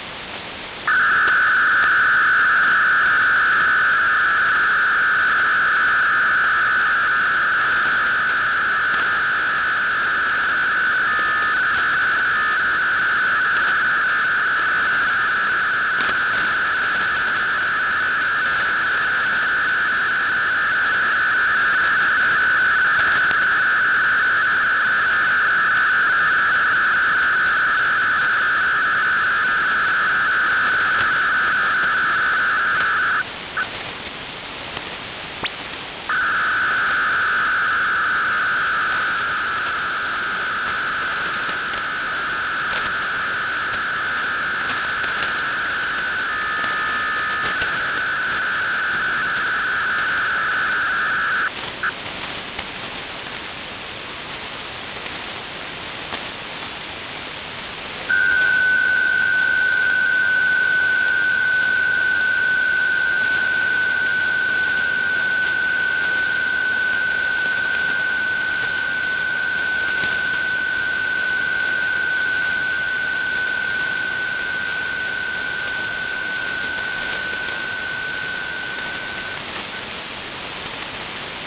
Начало » Записи » Радиоcигналы на опознание и анализ
На опознание DBPSK 100/500 Бод. Частота 9050 kHz.